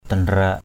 /d̪a-nra:ʔ/ (t.) mới gieo. padai danrak p=d dnK ruộng lúa mới gieo.